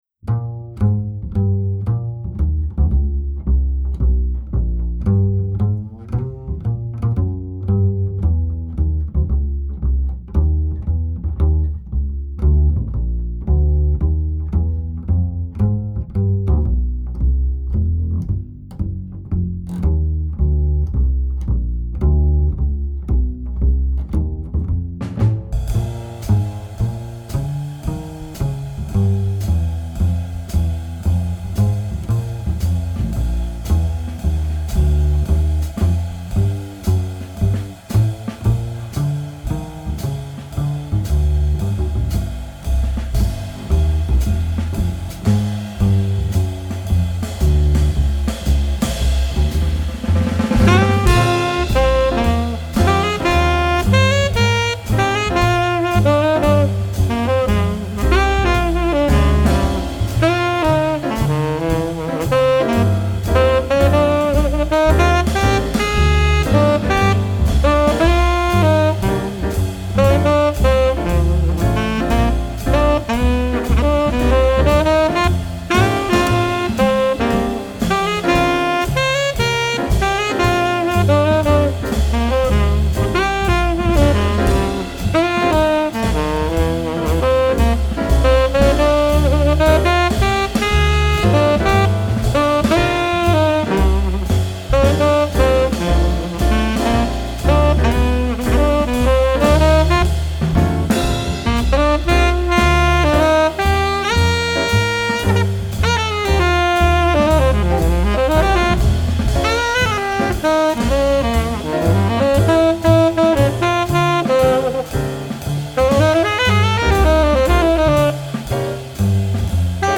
jazz album
The musicians and sound quality are wonderful.